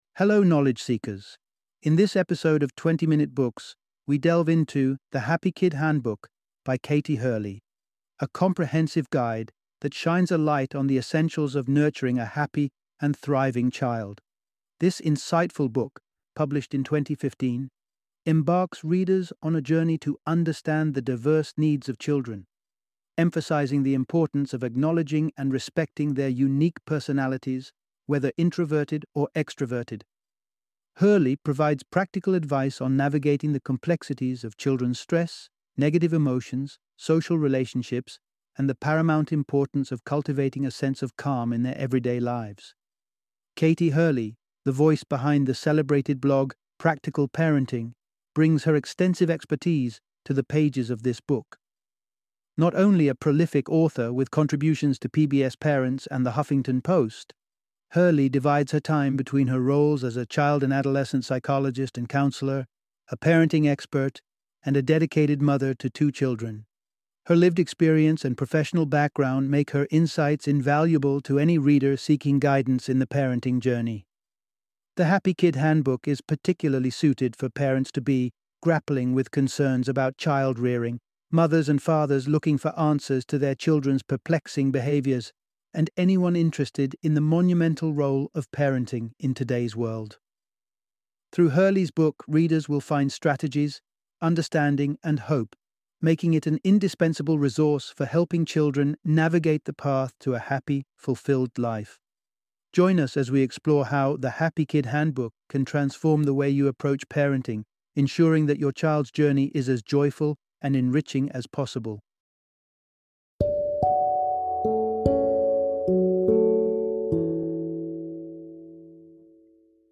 The Happy Kid Handbook - Audiobook Summary